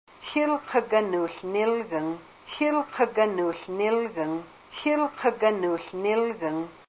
The following are a collection of phrases recorded with native speakers of Haida during a trip to Ketchikan and Hydaburg, Alaska, in March, 2003.
a native speaker of the Kasaan dialect of Alaskan Haida.